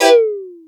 HarpsiKord-E3.wav